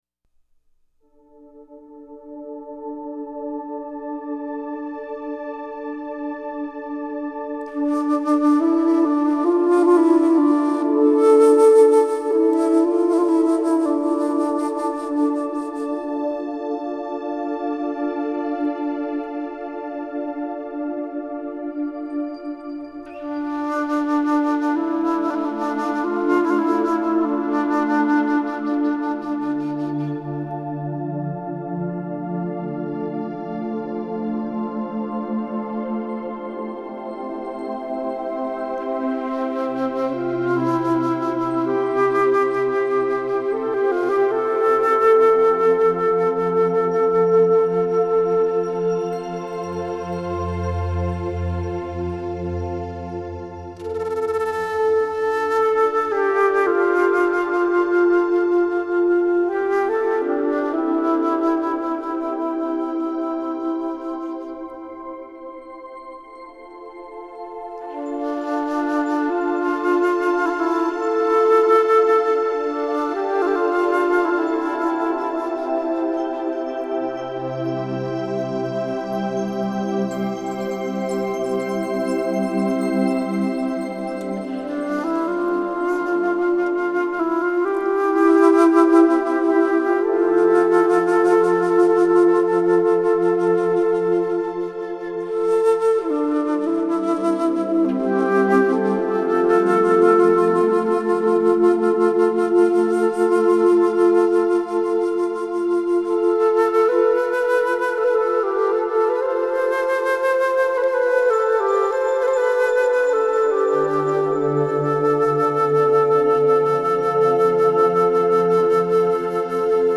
Нью эйдж
New age